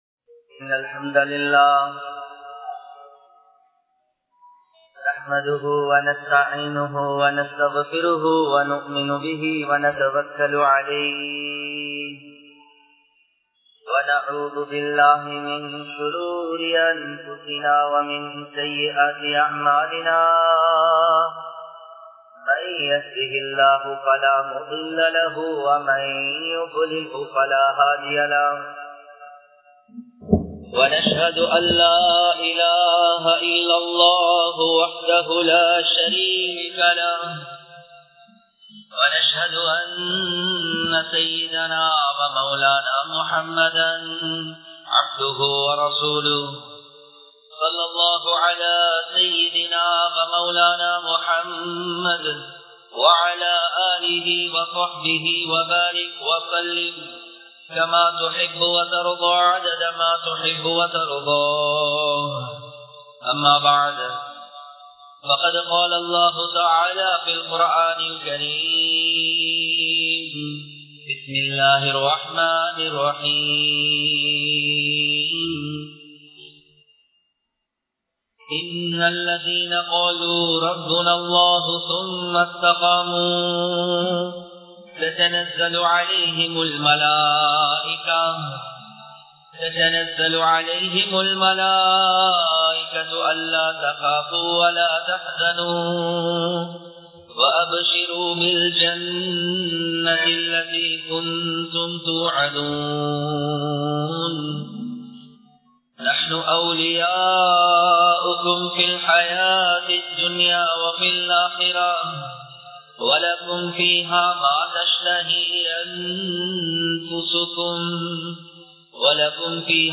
Makthabin Avasiyam (மக்தபின் அவசியம்) | Audio Bayans | All Ceylon Muslim Youth Community | Addalaichenai
Galle, Khilir Masjidh